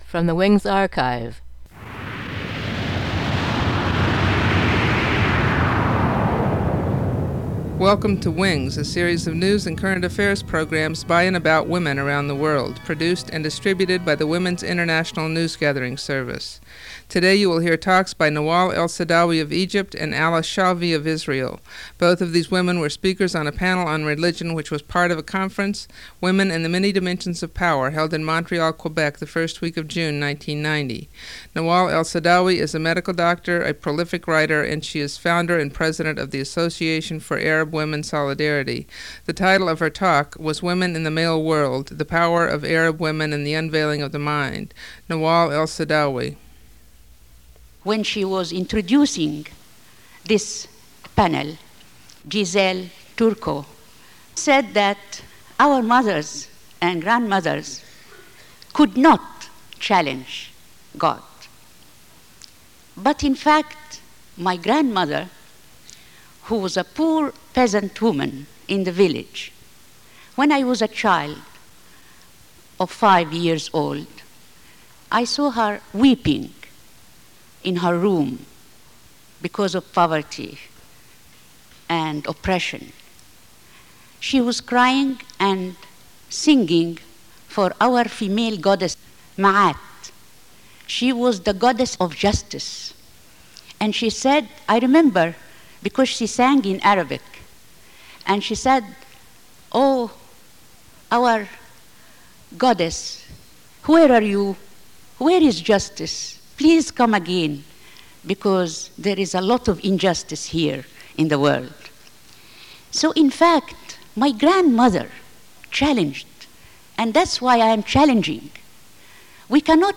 Speeches by Nawal El Saadawi of Egypt and Alice Shalvi of Israel, speaking about religion at the First World Summit on Women and the Many Dimensions of Power, in Montreal, Quebec, Canada, June 1990.